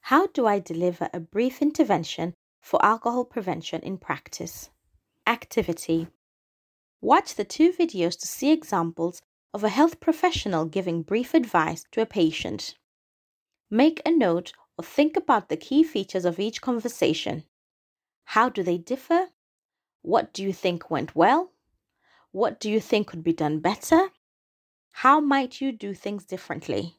Watch the two videos to see examples of a health professional giving brief advice to a patient.